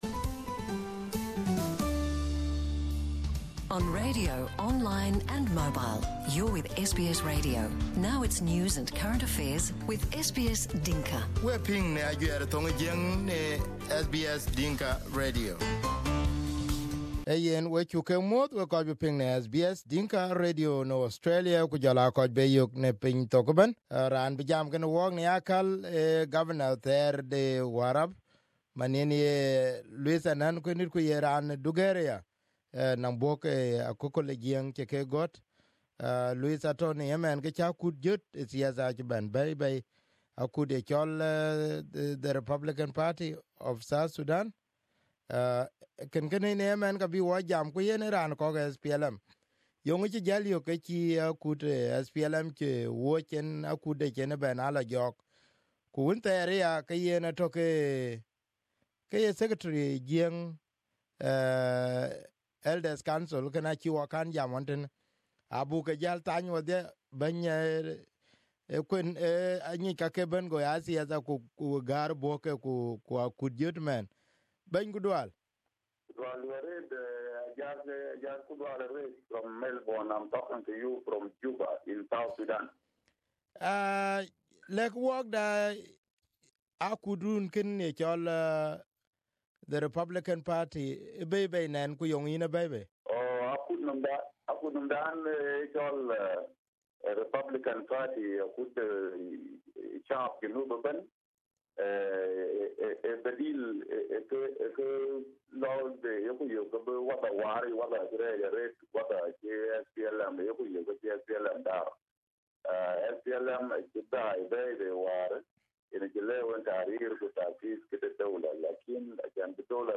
Luis Anei at SBS studio Source